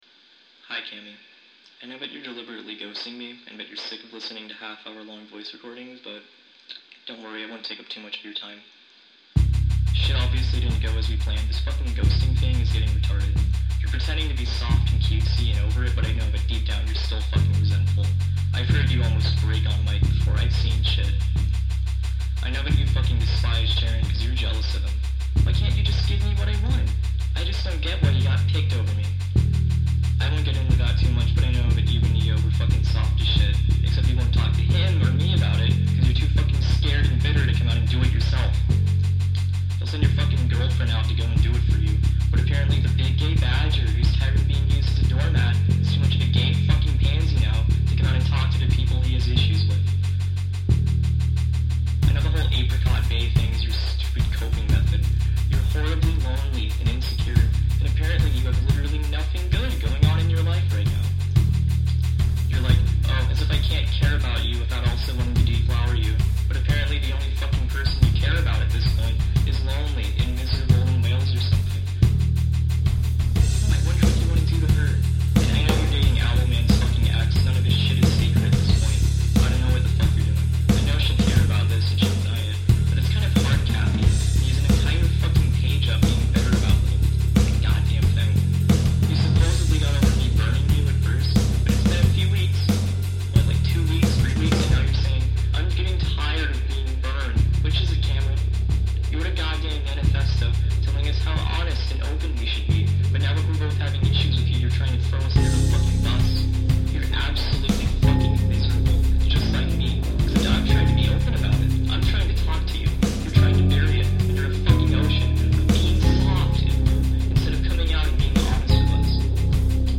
Recollections, remastered and reworked.
Overall? Dreamy.